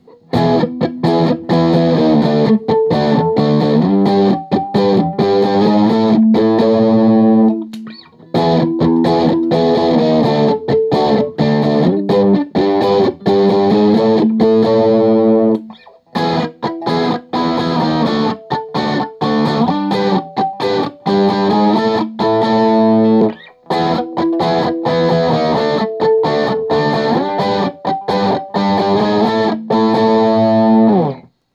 Each recording goes though all of the pickup selections in the order: neck, both (in phase), both (out of phase), bridge.
J800 A Barre Chords
I recorded the difference with the second set of recordings with the pickups farther from the strings.